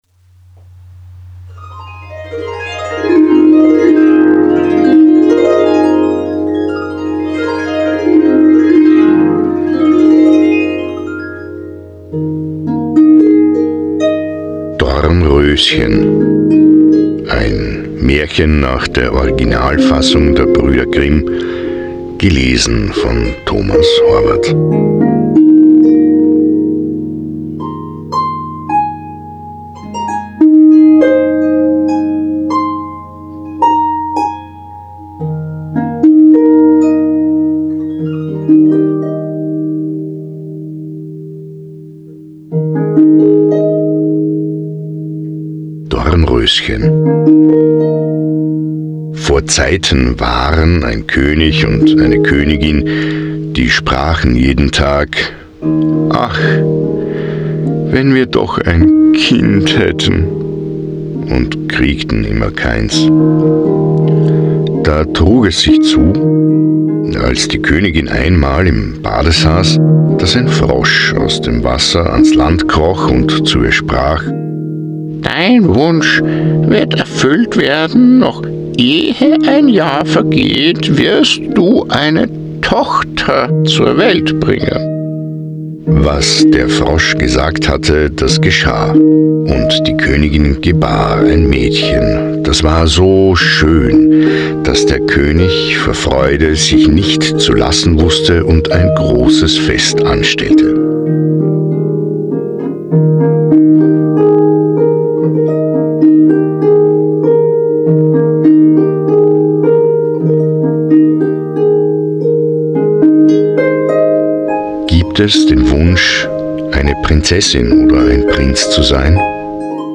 Musikimprovisation
Korr_Meditieren_mit_Maerchen_Hoerbeispiel.mp3